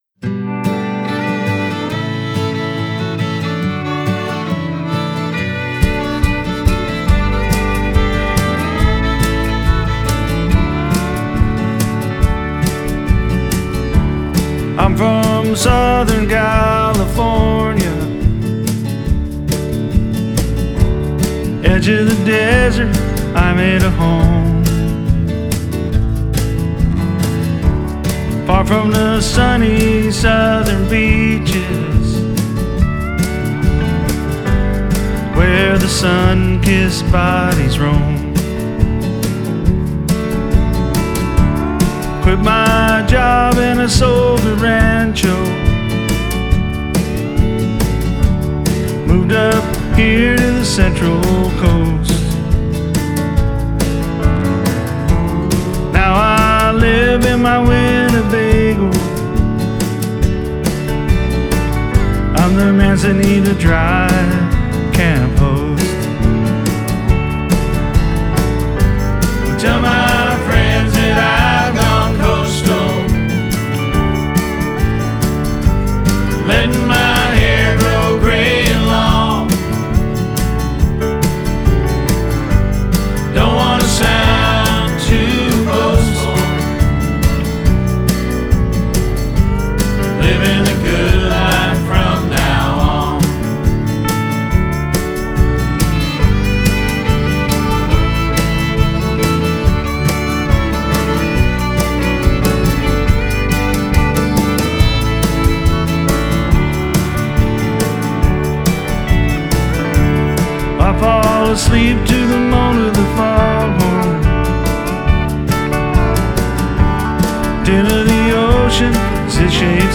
vocals, Acoustic guitar
MSA pedal steelel